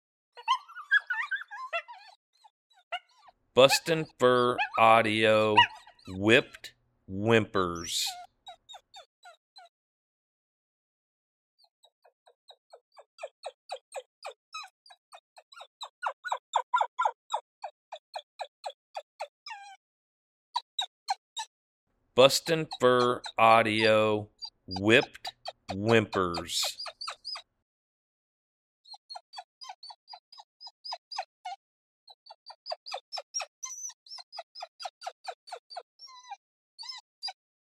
Male Coyote whimpering, this sound has worked well in close encounter situations!
BFA Whipped Whimpers Sample.mp3